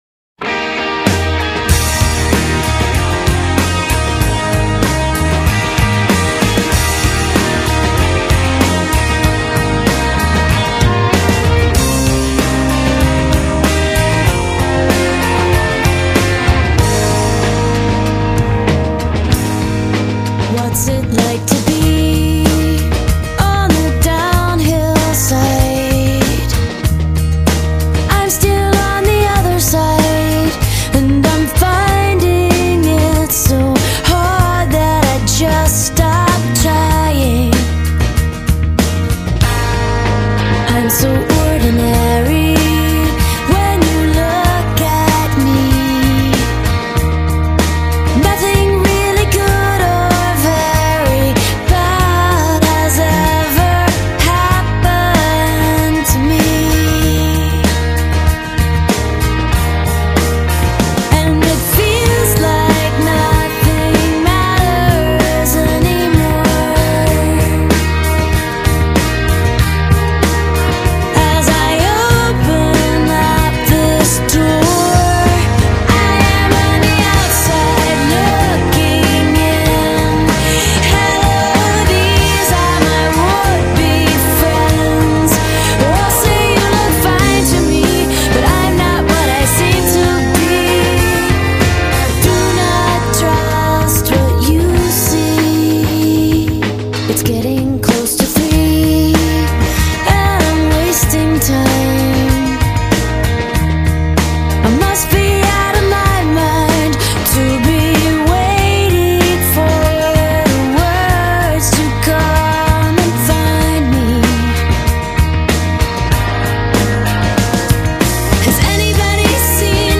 • Genre: Pop